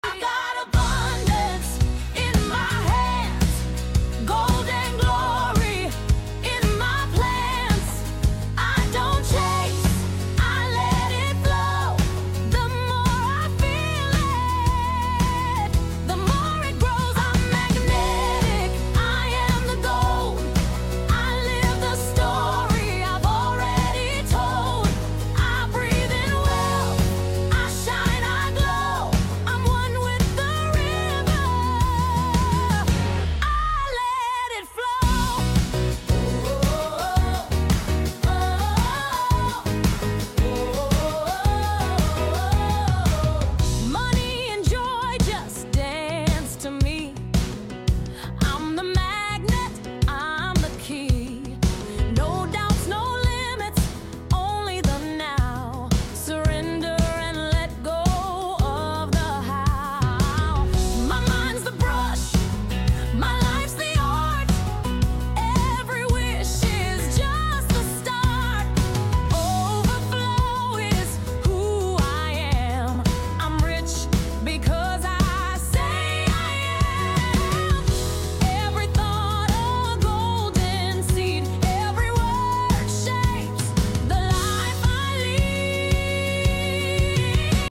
manifestation music, gospel music.